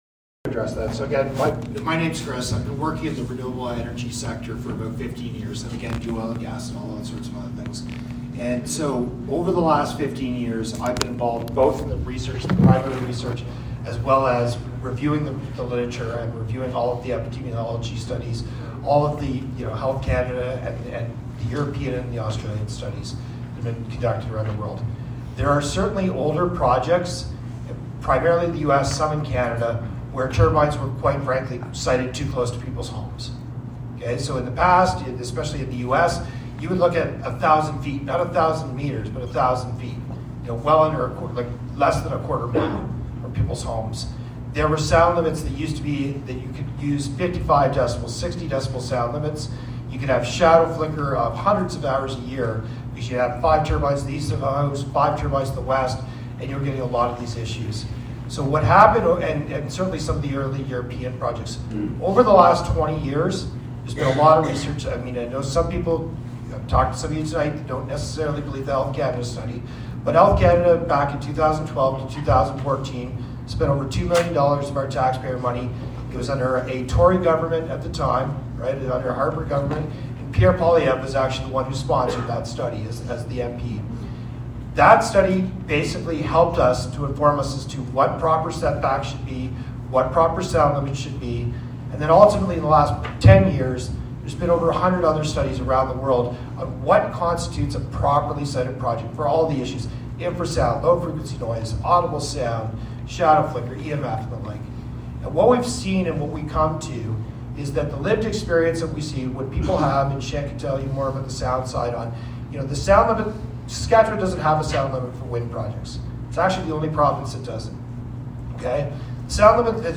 A number of concerns related to the long-term health effects of wind turbines for humans and wildlife were able to be addressed by a panel of experts at the town hall style community engagement event held Wednesday night at McKenna Hall by Enbridge, the proponents of the Seven Stars Energy project.